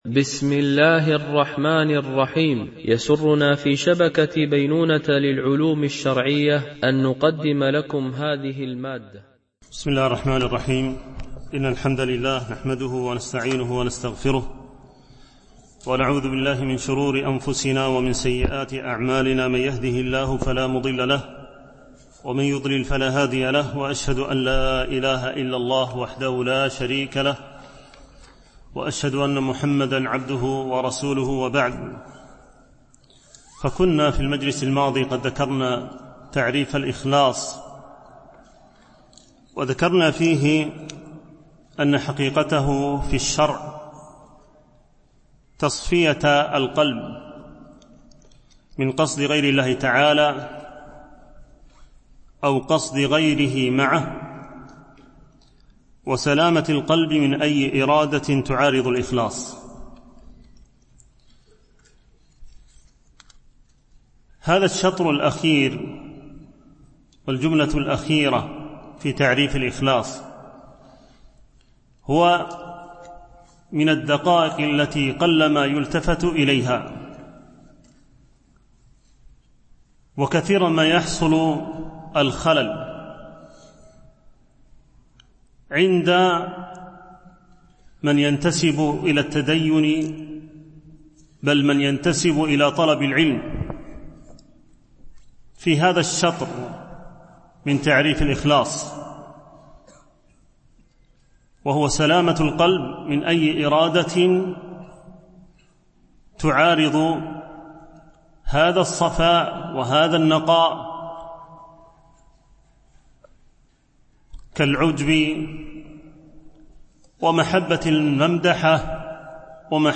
دورة علمية شرعية، بمسجد أم المؤمنين عائشة - دبي (القوز 4)